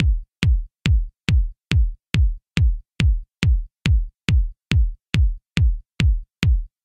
I’ve taken the drum out of the back of the Redrum on its dedicated output, and I’ve applied a little compression and EQ, just to keep things nice and solid.
The compressor is acting to tighten the drum, holding it in place, then I’ve dialed in a little bass at 94 Hz just to really make things thump.